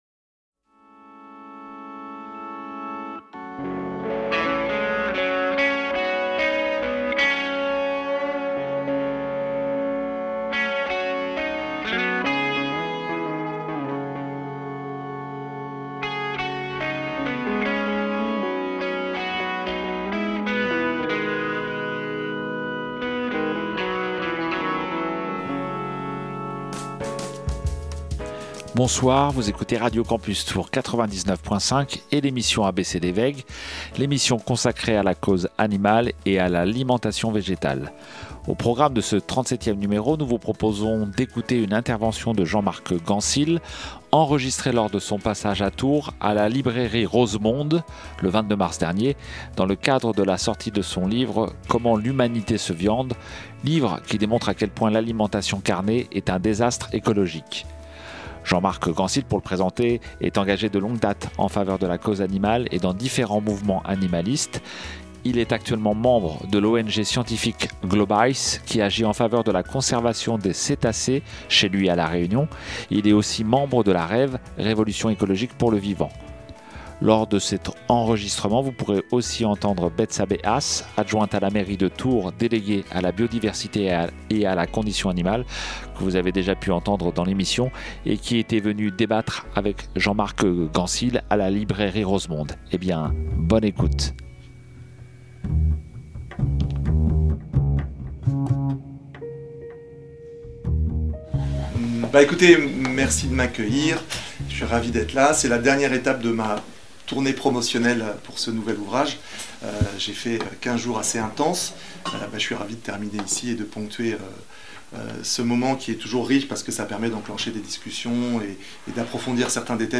le 22 mars dernier à la librairie Rosemonde à Tours